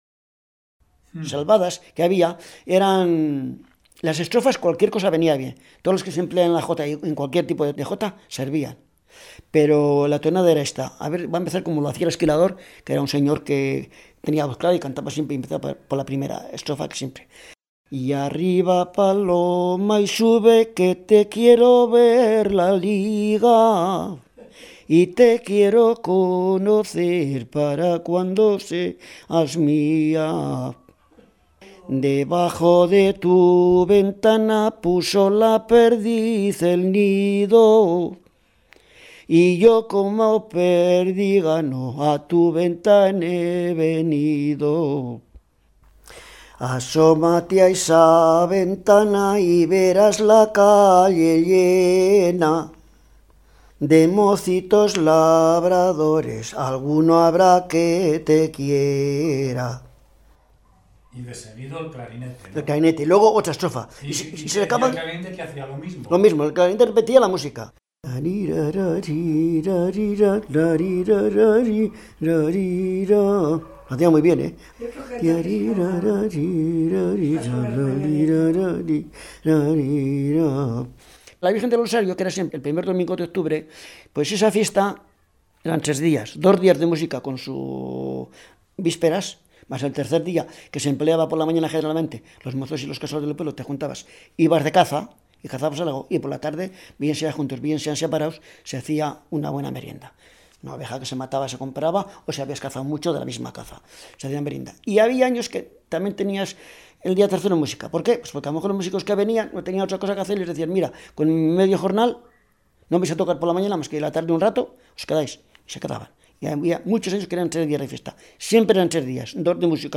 Clasificación: Cancionero
Como hemos visto en otros casos -alboradas de Grañón, albadas de Grávalos, enramadas de Pinillos, canciones de quintos en Cameros– la melodía de estos cantos, equiparables al genérico de albadas, es sencilla y bella a la vez.